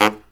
LOHITSAX11-R.wav